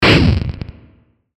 シンセ 8bit 爆発mix
ドゴン